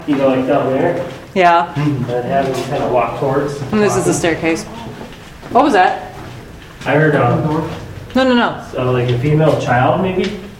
Basement – 11:33 pm
A audible woman’s voice is captured while two investigators discuss movement